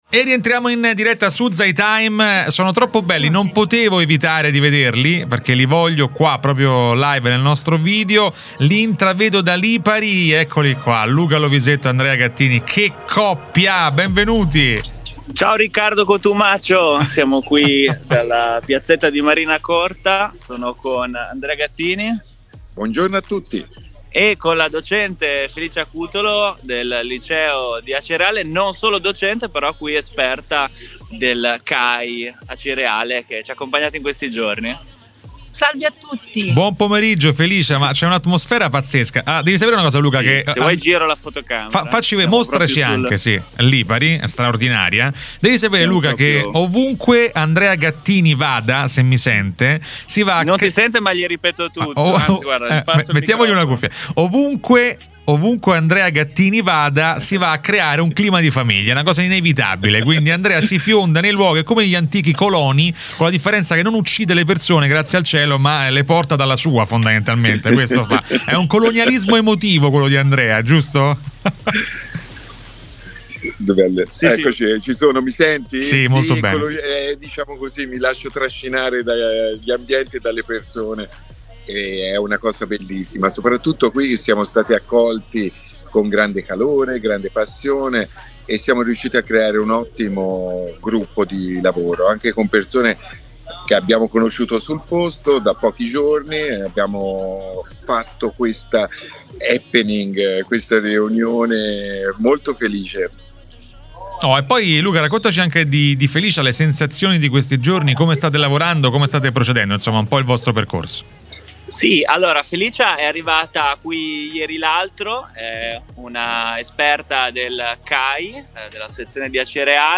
In collegamento dall'Istituto Comprensivo Isole Eolie, con noi, per parlare del progetto "Acqua Aria Terra Fuoco"